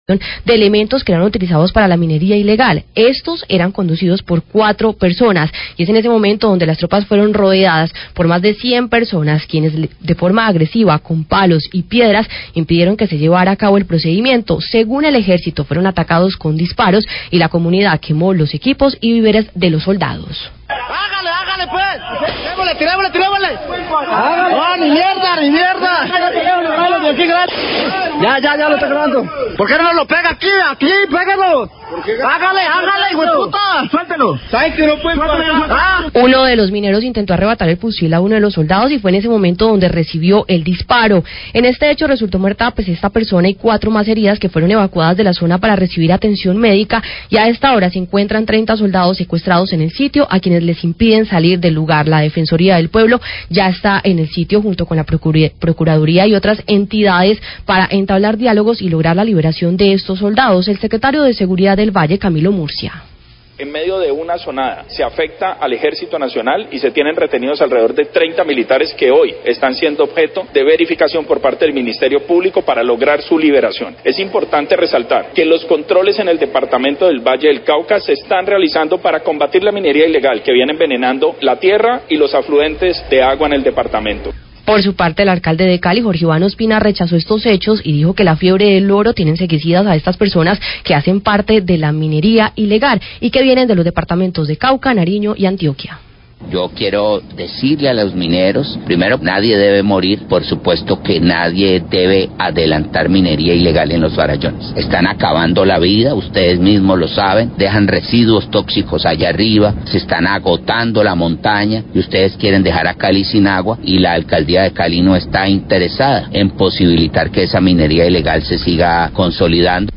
Srio Seguridad Valle y Alcalde Cali hablan de enfrentamiento entre militares y mineros ilegales en Peñas Blancas
Radio